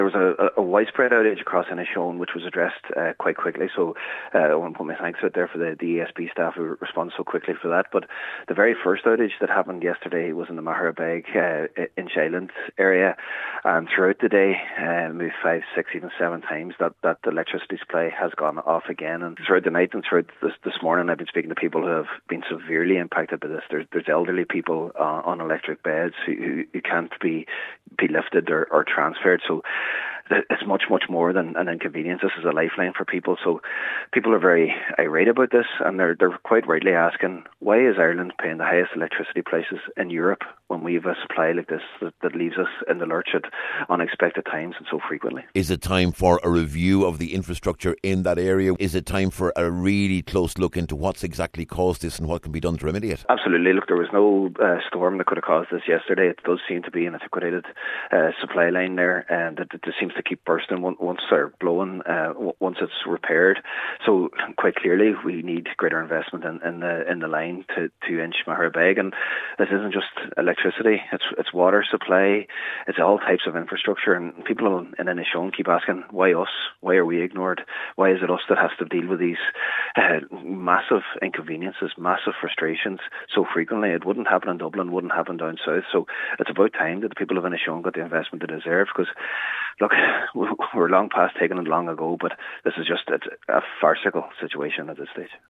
Cllr Jack Murray says the people of the area need answers……..